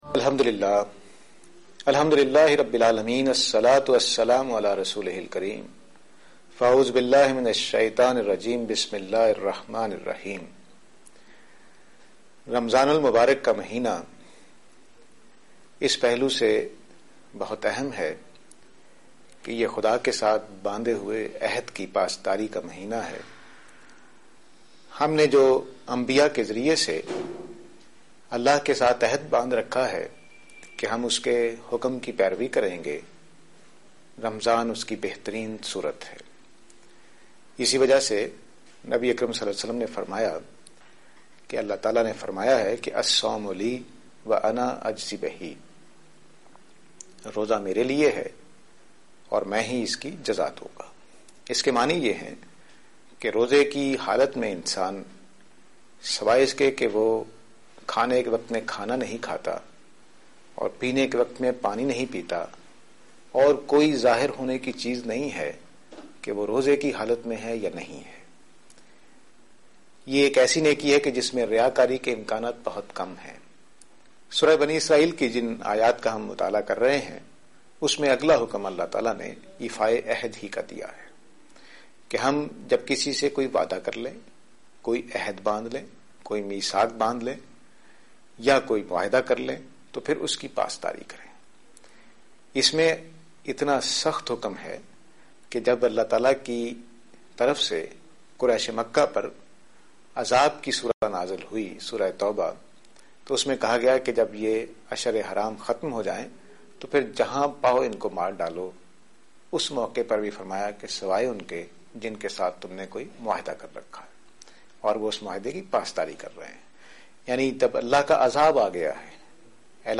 A short talk